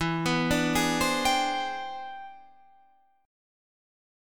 E 7th Flat 13th Sharp 9th